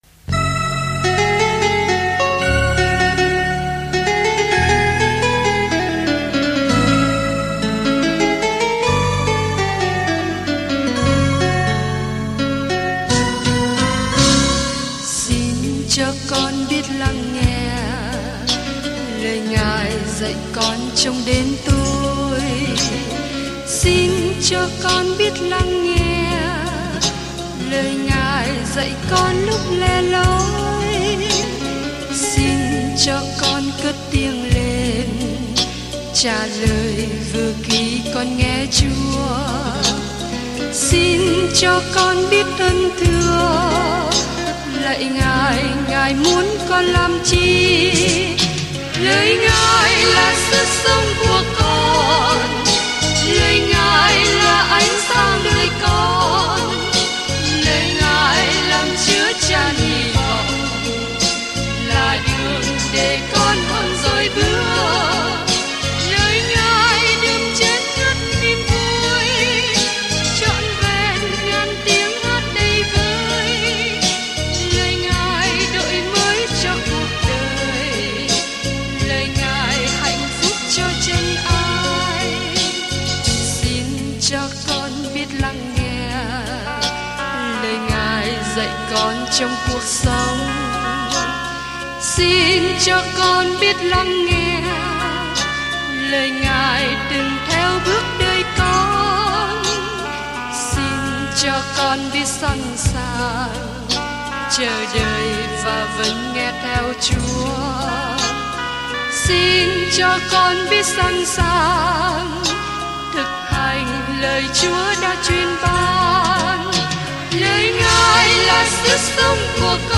Kinh Thánh Mác 10:1-31 Ngày 11 Bắt đầu Kế hoạch này Ngày 13 Thông tin về Kế hoạch Phúc âm ngắn hơn của Mác mô tả chức vụ trên đất của Chúa Giê-su Christ với tư cách là Người đầy tớ đau khổ và Con Người. Du lịch hàng ngày qua Mác khi bạn nghe nghiên cứu âm thanh và đọc những câu chọn lọc từ lời Chúa. More Chúng tôi xin cảm ơn Thru the Bible đã cung cấp kế hoạch này.